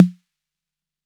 Snare 808 1.wav